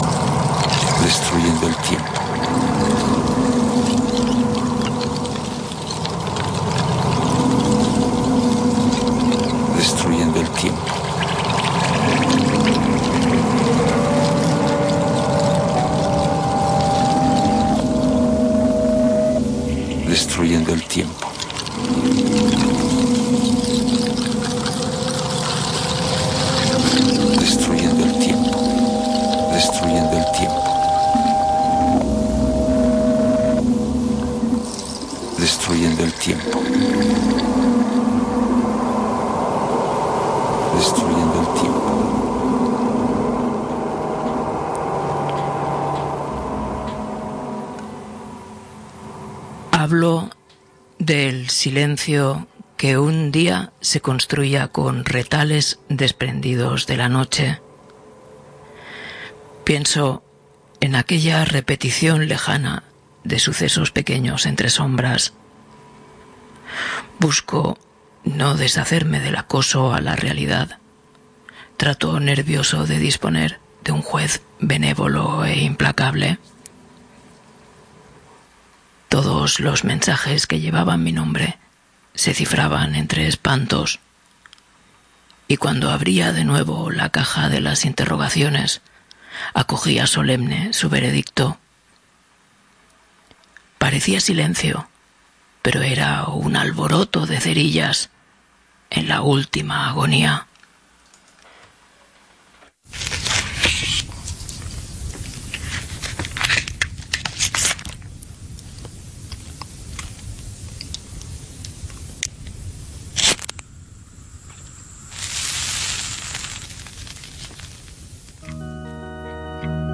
También nos acordamos de Antonio Tabucchi, a quien acompañamos con cariño hasta la puerta del otro lado de la tumba. Leemos dos de sus «Sueños de sueños», el de Rimbaud y el de Caravaggio.